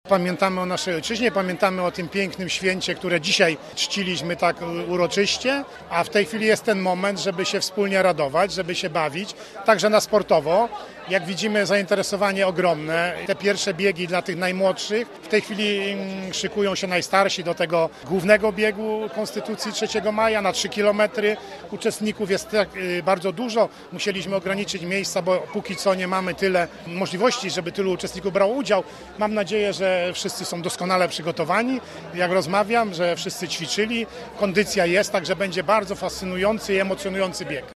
– W ten sposób także można pokazywać swój patriotyzm – mówił wojewoda Władysław Dajczak: